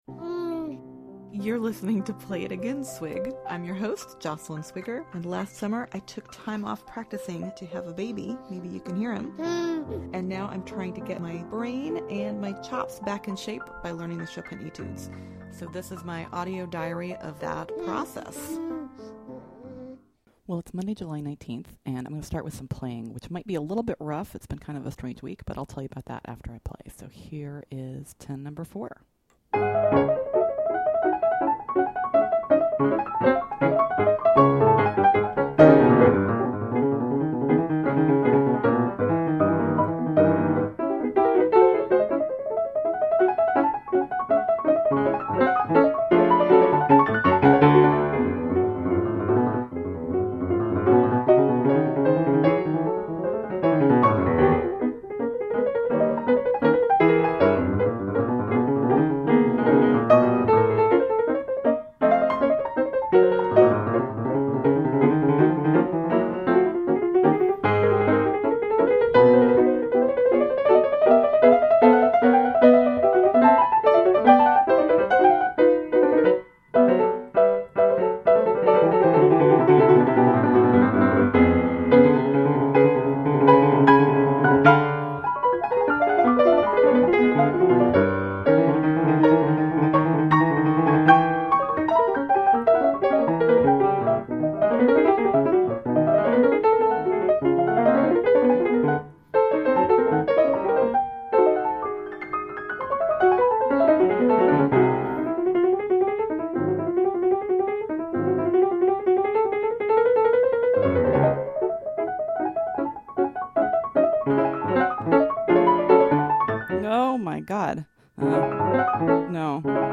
Play-throughs of 10/4 and 10/8; 25/12 both simplified and as written, and a quick exploration of its structure; a brief cameo appearance from a special guest star.